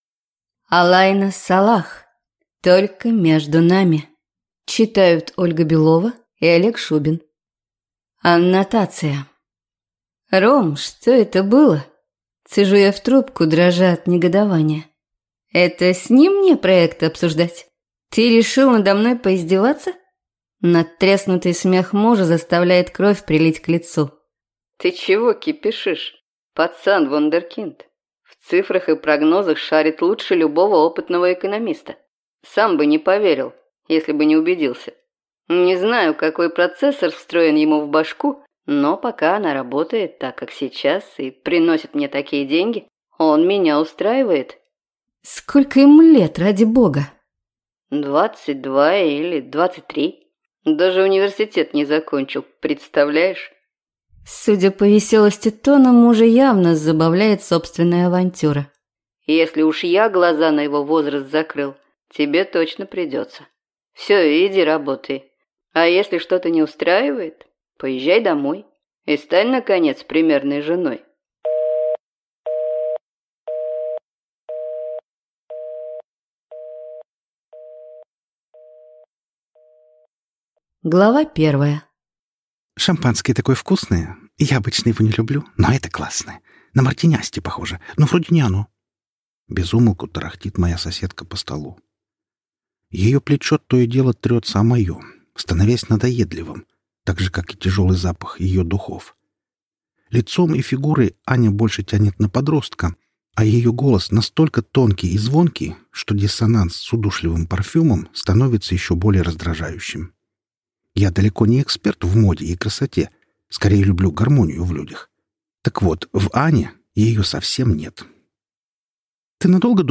Аудиокнига Только между нами | Библиотека аудиокниг
Прослушать и бесплатно скачать фрагмент аудиокниги